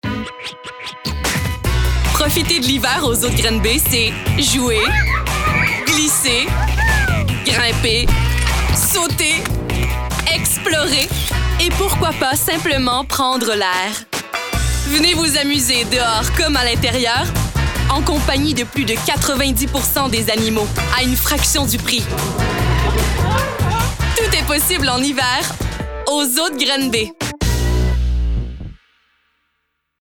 Démos voix